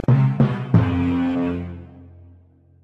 PLAY Dramatic Sting New 2
Dramatic-Sting-2.mp3